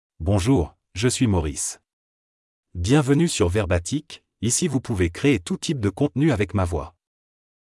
Maurice — Male French (France) AI Voice | TTS, Voice Cloning & Video | Verbatik AI
Maurice is a male AI voice for French (France).
Voice sample
Male
Maurice delivers clear pronunciation with authentic France French intonation, making your content sound professionally produced.